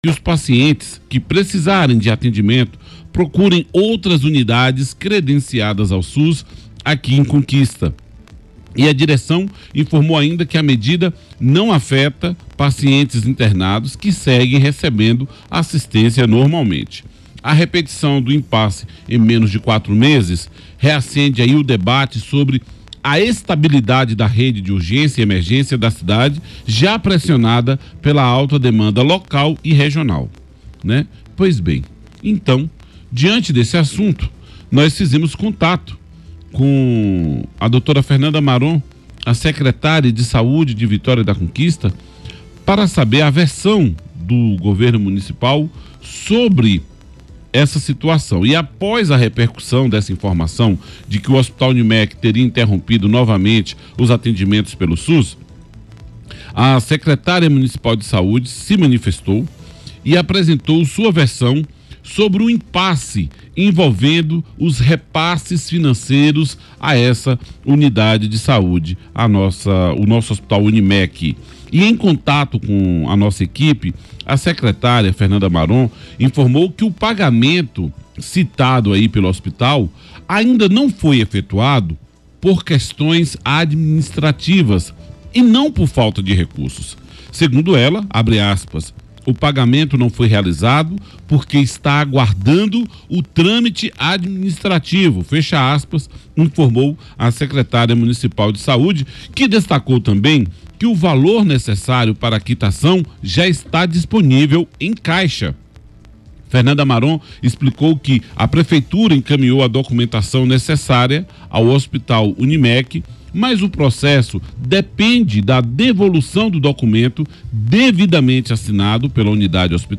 A secretária de Saúde, Fernanda Oliveira Maron, rebateu as informações em entrevista ao programa Brasil Notícias nesta segunda-feira (27). Segundo a gestora, o recurso necessário para o repasse já se encontra em caixa, mas o pagamento não ocorreu devido a entraves burocráticos.